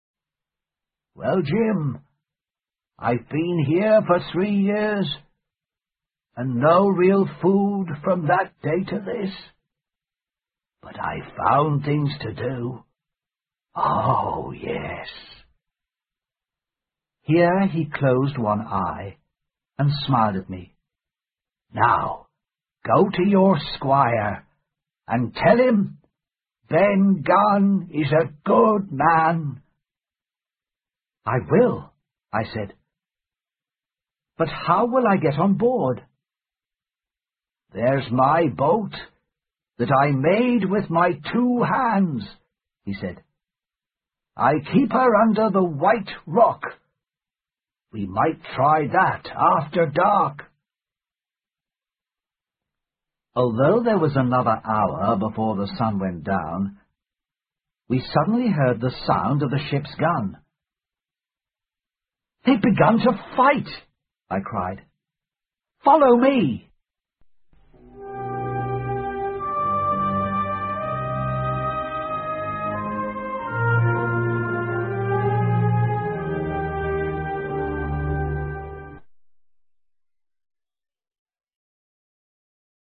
在线英语听力室《金银岛》的听力文件下载,《金银岛》中英双语有声读物附MP3下载